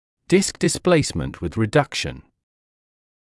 [dɪsk dɪs’pleɪsmənt wɪð rɪ’dʌkʃn][диск дис’плэйсмэнт уиз ри’дакшн]смещение диска с вправлением